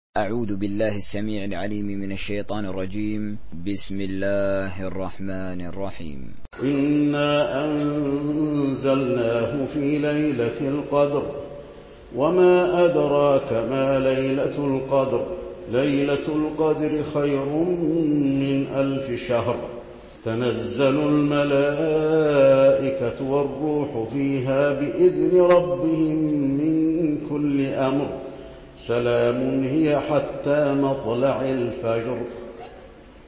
تحميل سورة القدر حسين آل الشيخ تراويح